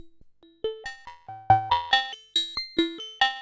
tx_synth_140_ringmod_AMin.wav